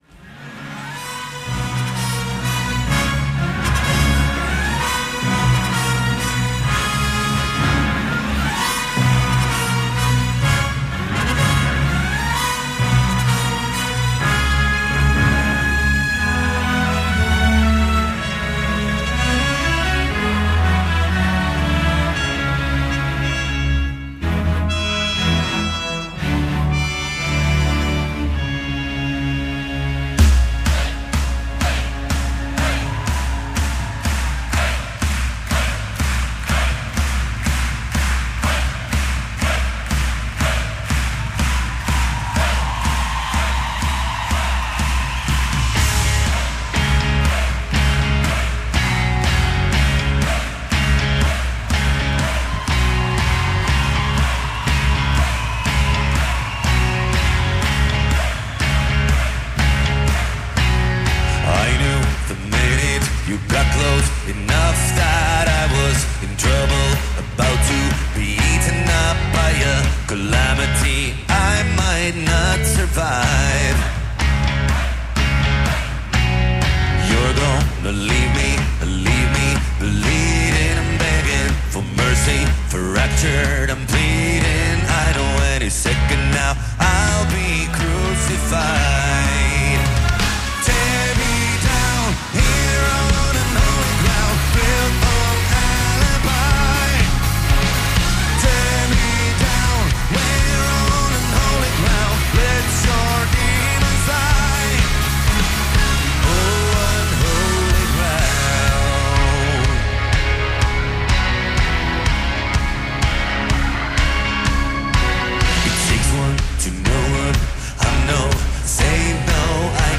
Finnish Pop with a 90 piece orchestra.